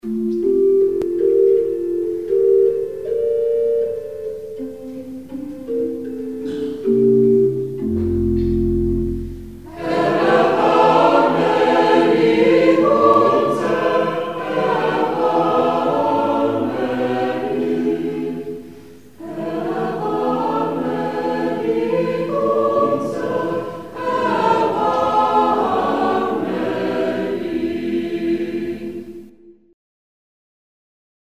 3-teilig, eventuell mit Gemeindebeteiligung